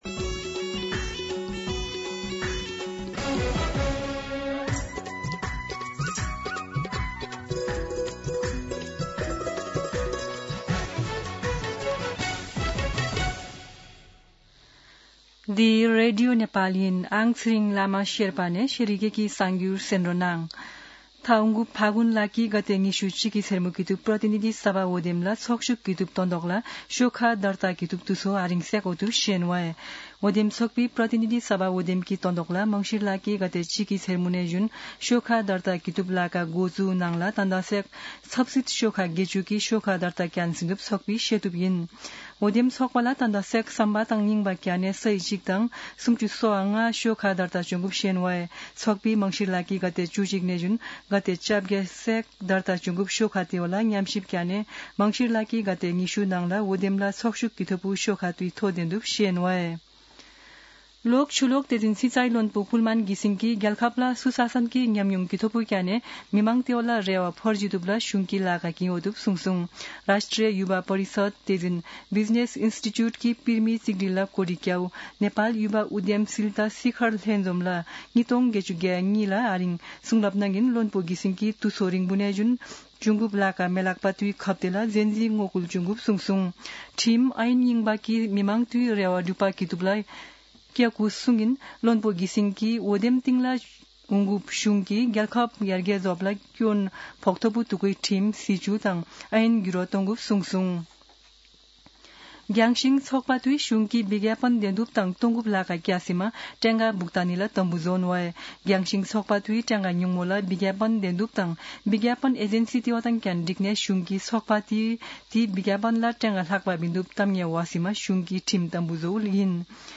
शेर्पा भाषाको समाचार : १० मंसिर , २०८२
Sherpa-News-10.mp3